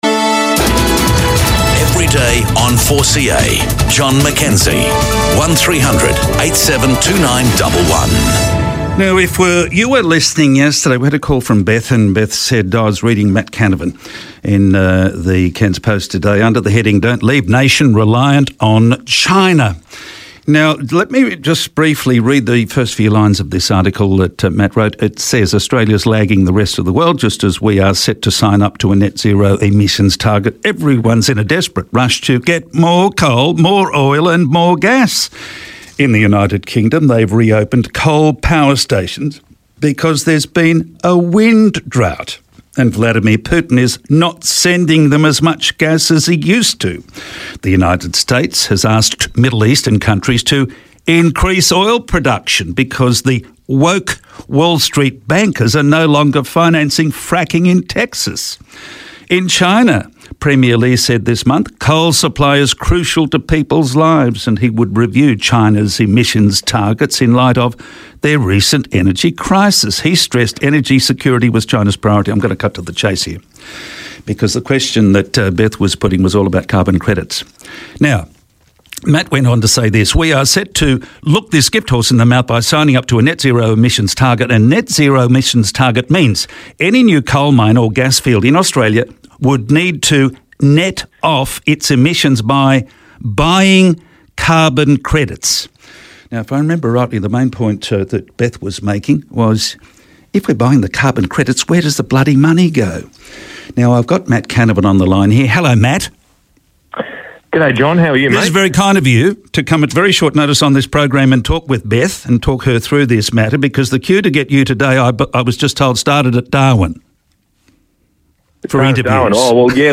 Today I spoke with Senator Matthew Canavan, Deputy Leader of The Nationals in the Senate, about the reality of carbon credits.